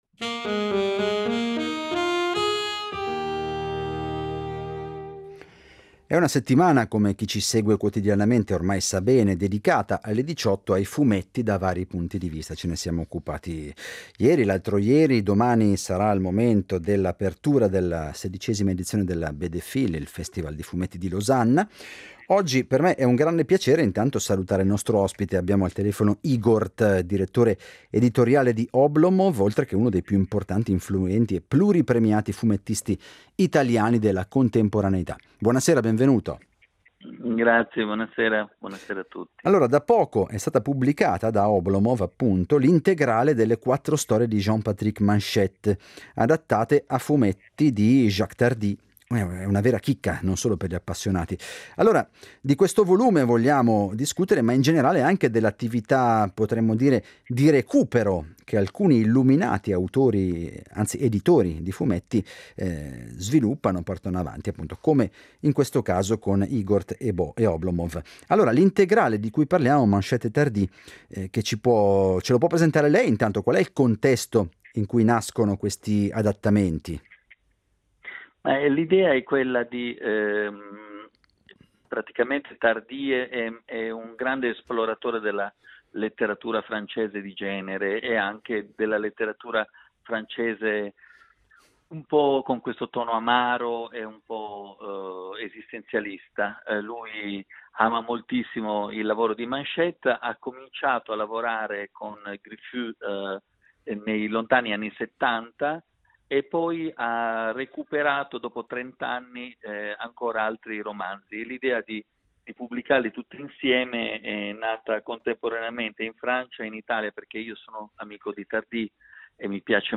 Proveremo ad approfondire alcuni aspetti del fumetto contemporaneo: l’ospite di oggi è Igort, uno dei più importanti e pluripremiati fumettisti e artisti italiani contemporanei.